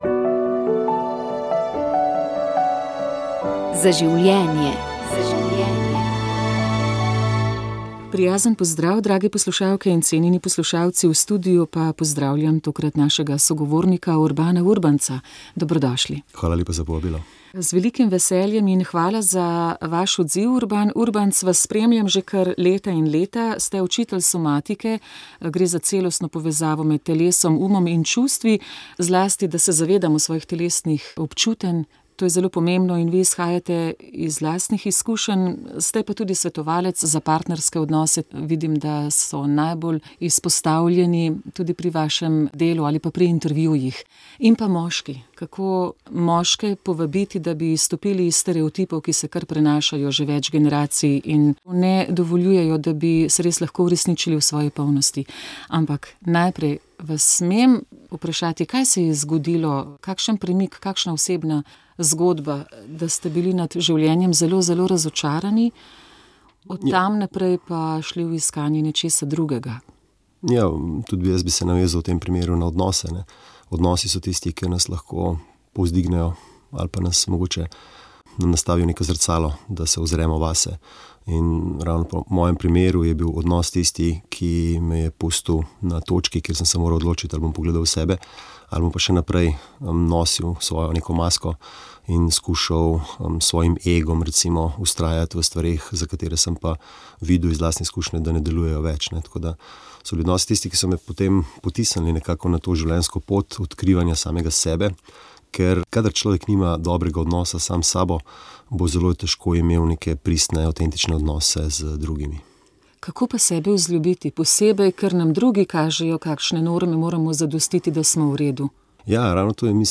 Na praznik Marijinega oznanjenja smo prenašali slovesno sveto mašo iz Nove Štifte pri Gornjem Gradu.